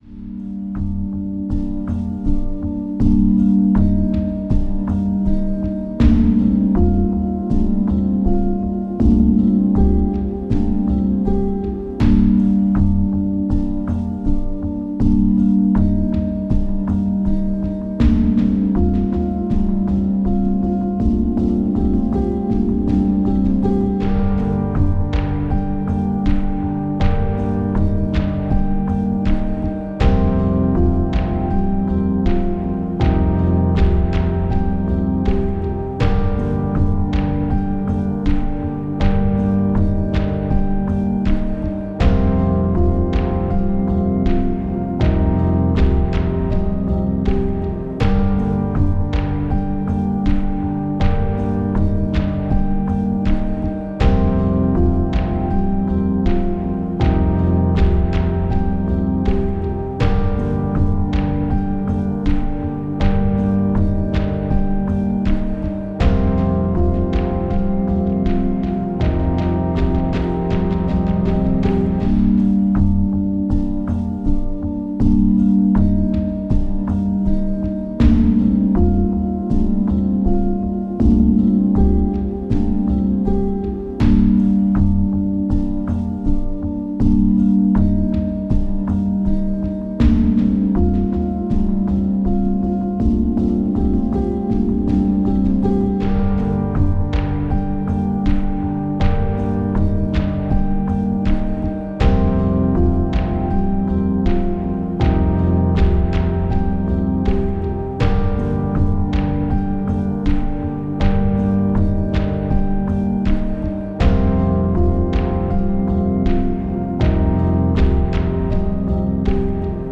une ambiance sombre et poignante
entre mélancolie et frissons.